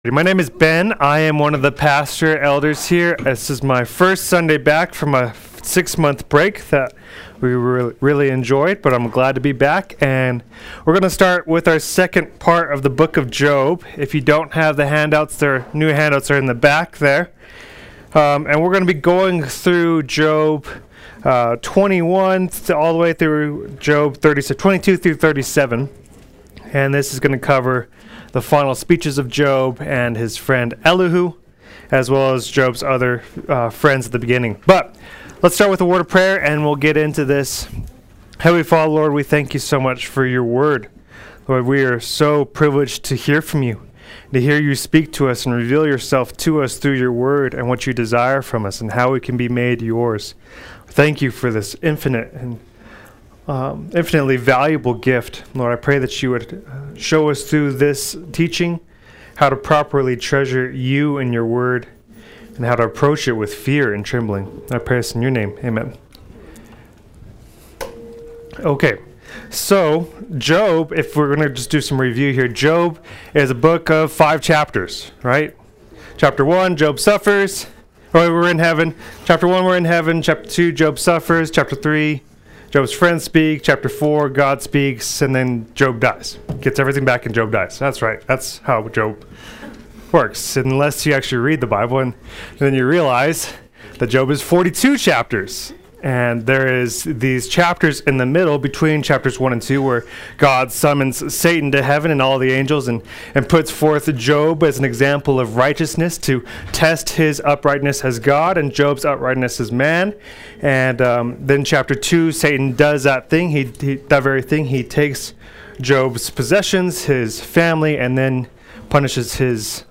Play Sermon Get HCF Teaching Automatically.
Job 22:1-37:24 Adult Sunday School